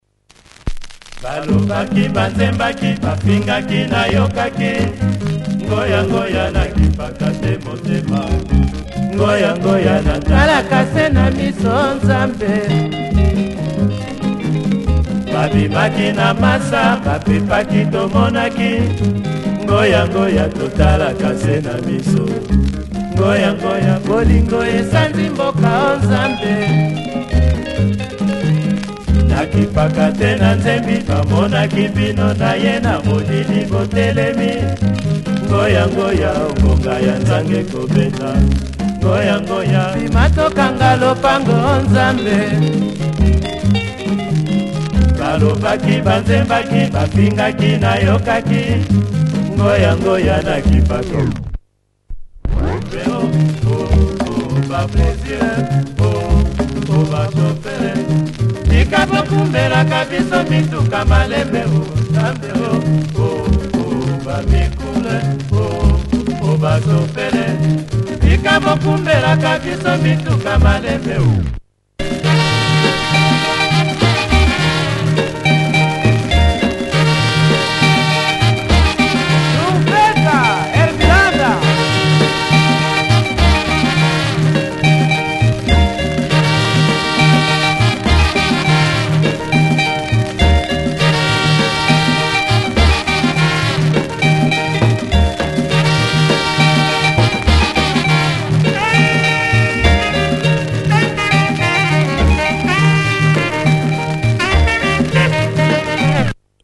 Congo in Kenya, check audio for both sides.
Could do with an extra clean.